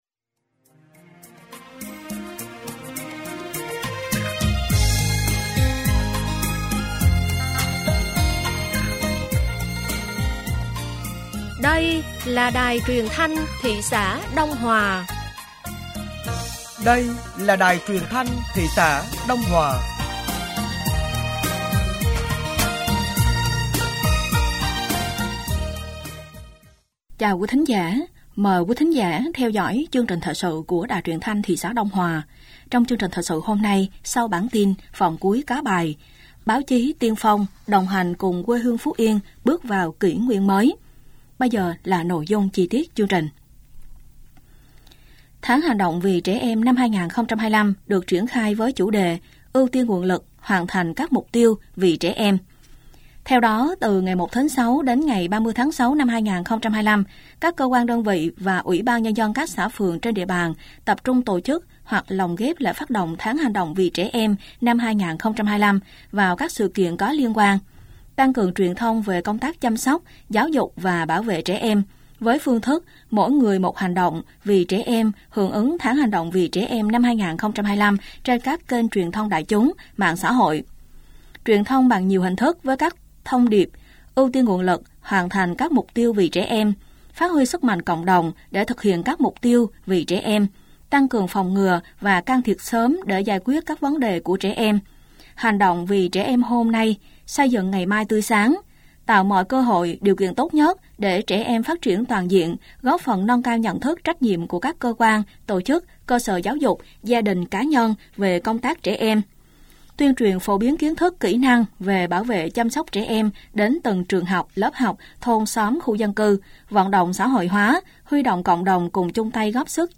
Thời sự tối ngày 17/6/2025 sáng ngày 18/6/2025